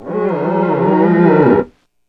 Index of /90_sSampleCDs/E-MU Producer Series Vol. 3 – Hollywood Sound Effects/Miscellaneous/Rubber Squeegees
SQUEEGEE 7.wav